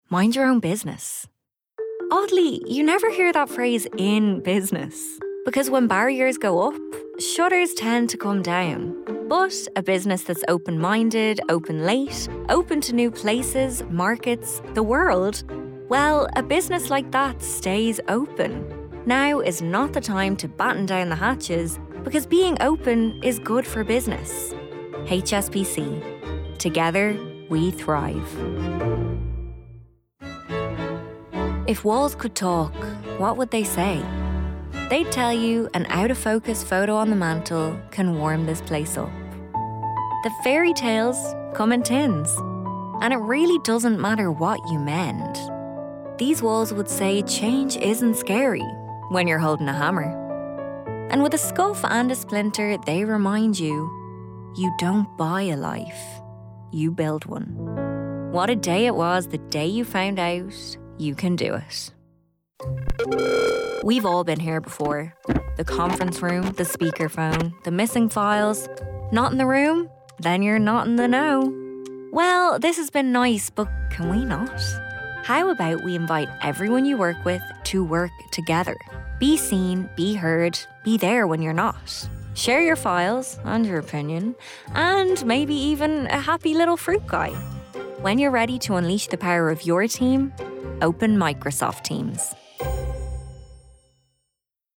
Voice notes: Youthful, vivacious, with impeccable comedic timing.
Alternative link 0:00 0:00 volume voice sampler Download 4MB Voice notes: Youthful, vivacious, with impeccable comedic timing.